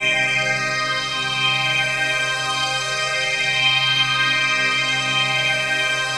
PHASEPAD05-LR.wav